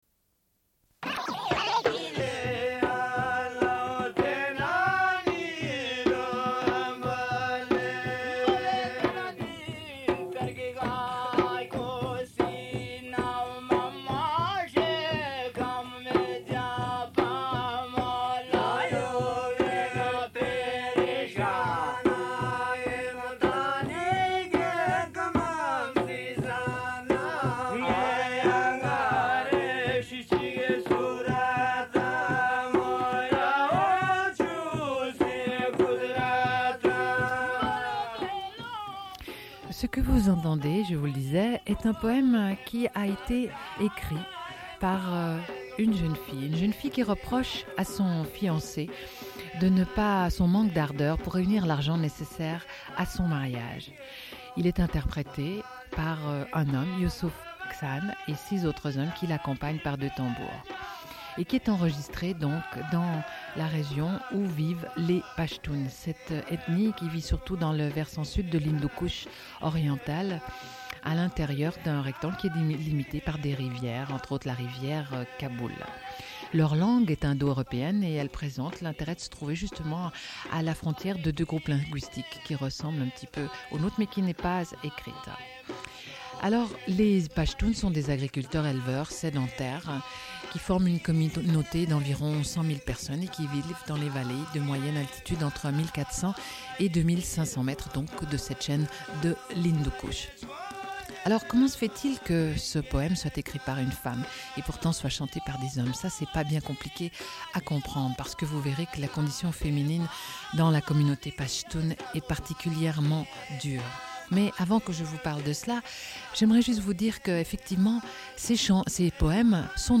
Une cassette audio, face A
Radio Enregistrement sonore